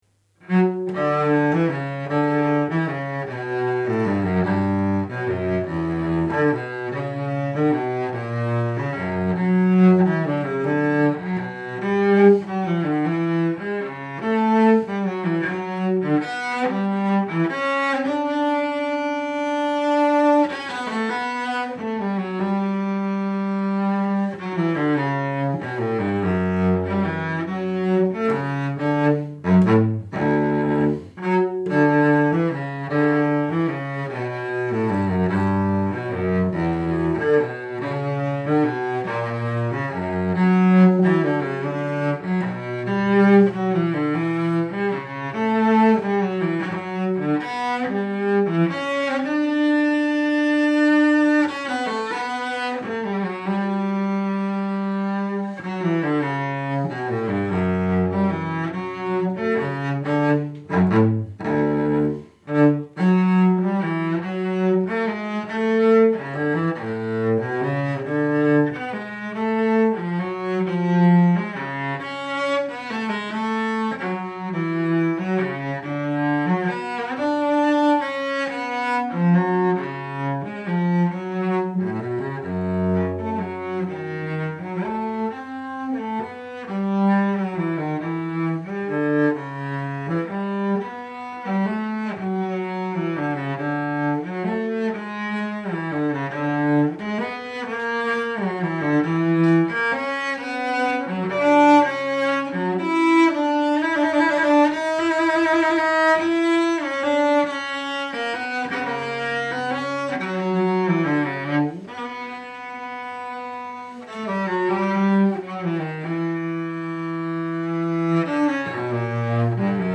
Bach_Cello_Suite_05_Gigue.mp3